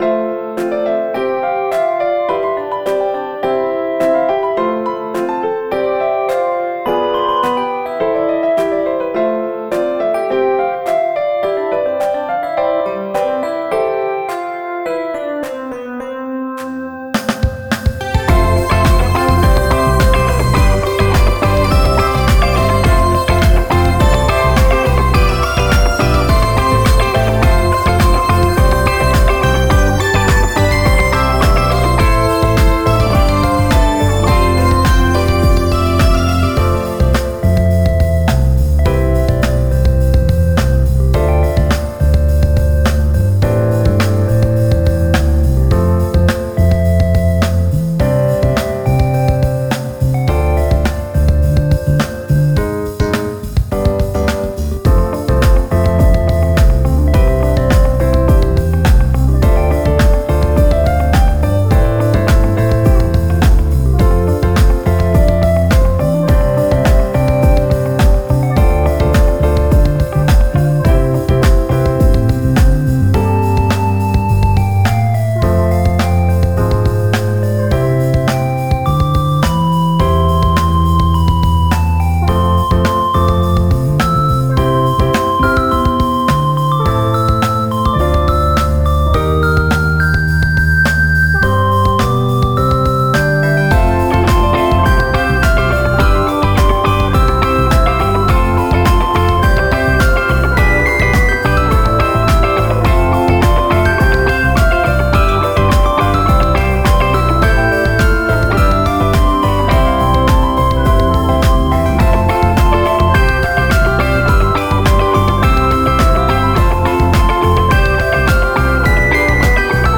• スタートはピアノでコードを打ちます
• ドラムを打ちます
• ベースを打ち込みます
※デモ段階の音源です、まだまだ手直しが必要ですね(;^_^A